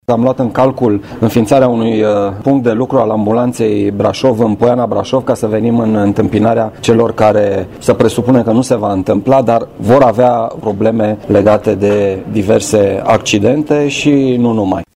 Astfel, prefectul Marian Rasaliu a anunțat intenția de a înființa un punct de lucru al Ambulanței Brașov în Poiană: